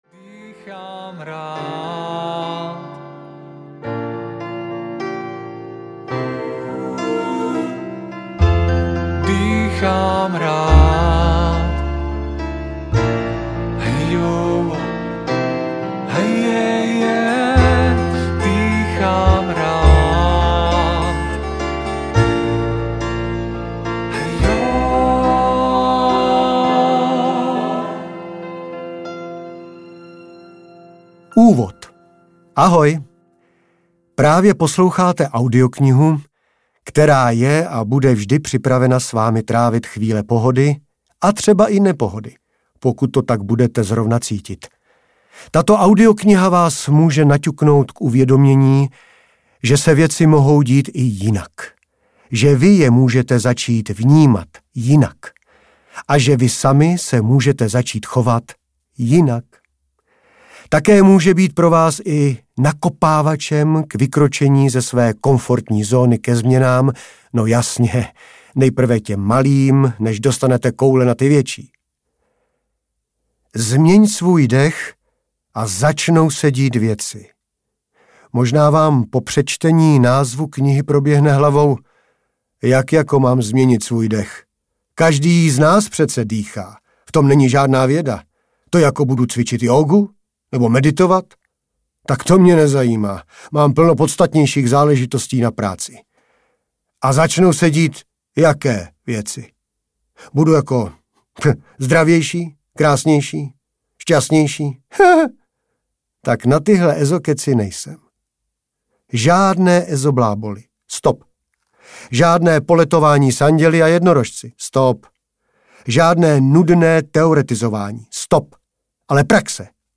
Změň svůj dech a začnou se dít věci audiokniha
Ukázka z knihy